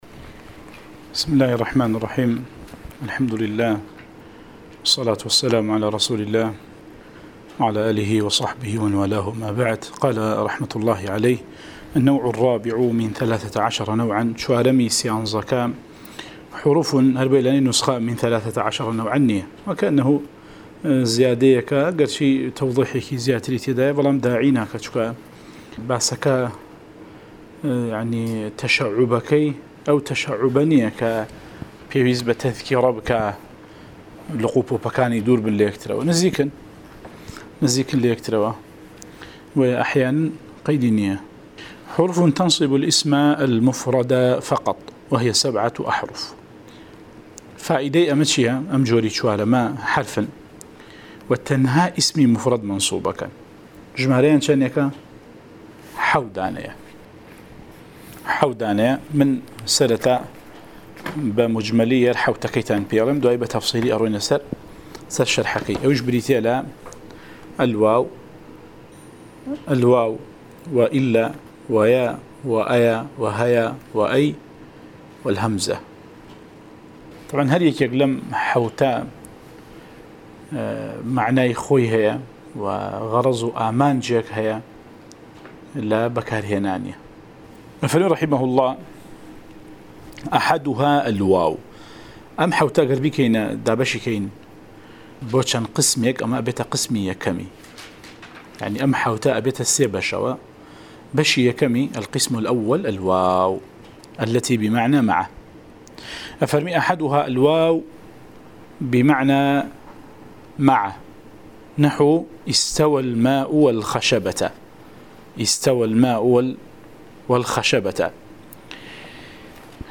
08 ـ شەرحی العوامل المائة، (عوامل الجرجانی) (نوێ) وانەی دەنگی: - شرح عوامل المائة (عوامل الجرجاني)